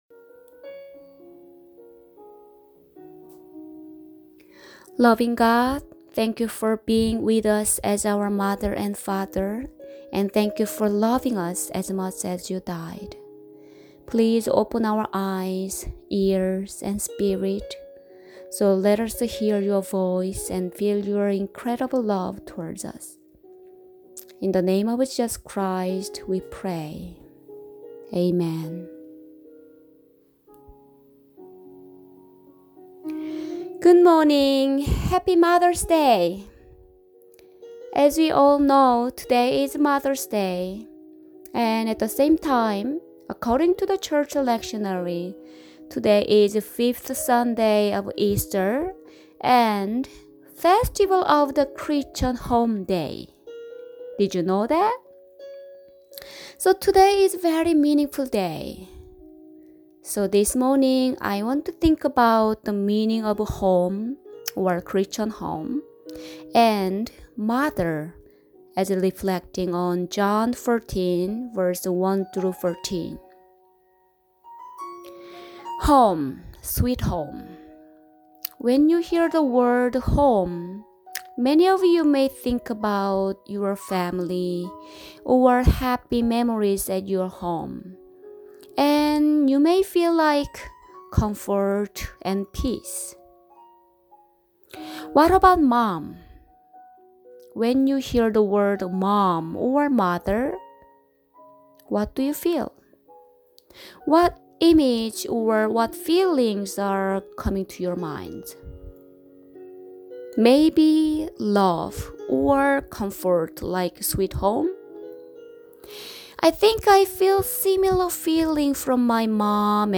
Preaching for Mother’s Day, May 10, 2020